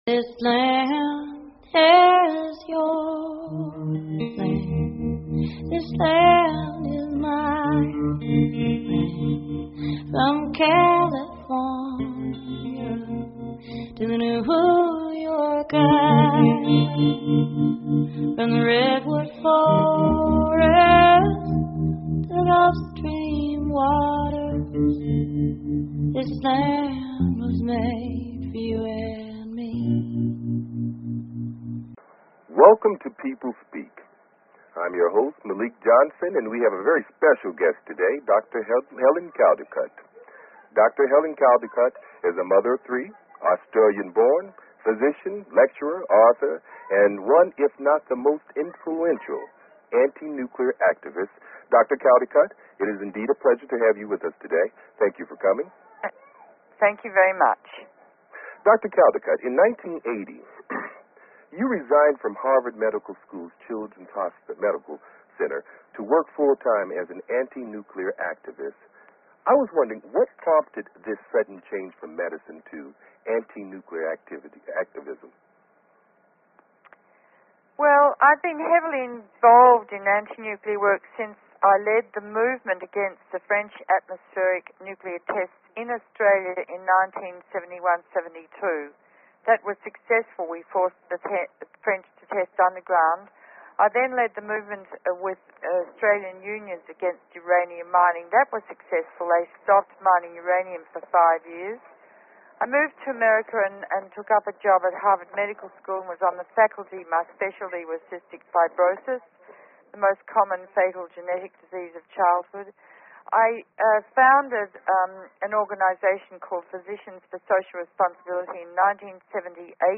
Guest, Dr Helen Caldicott